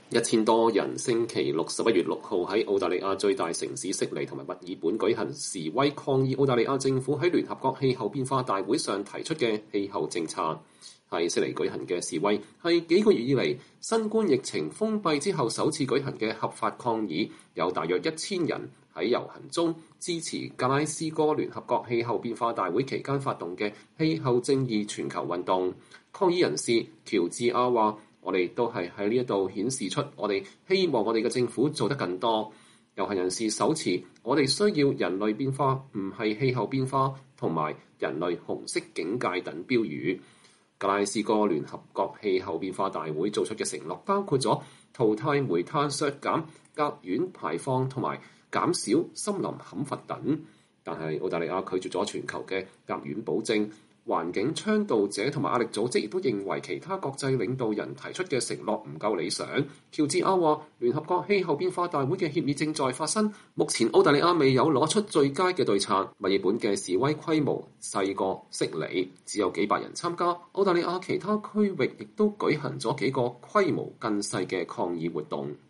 澳大利亞悉尼市一千多人示威，抗議政府的氣候政策（2021年11月6日）